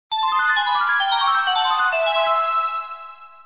WWW___BELL.mp3